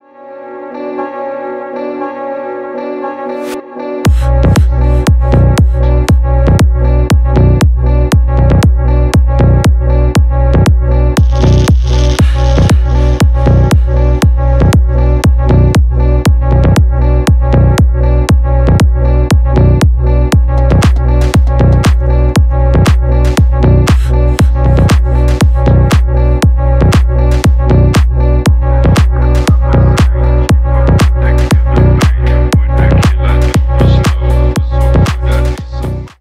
Электроника
клубные
громкие